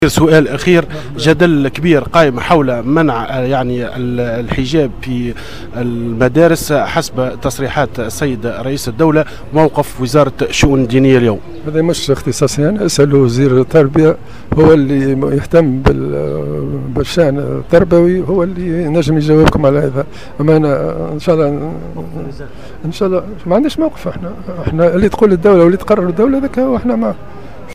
قال وزير الشؤون الدينية،عثمان بطيخ في تصريح ل"جوهرة أف أم" إن الوزارة ليس لها أي موقف بخصوص تصريحات رئيس الجمهورية الباجي قائد السبسي الأخيرة حول منع الحجاب داخل المدارس.
وأضاف الوزير على هامش لقاء جمعه بحجيج ولاية المهدية أن الموضوع ليس من اختصاصه وإنما يعود بالنظر لوزير التربية الذي يهتم بالشأن التربوي، وفق تعبيره.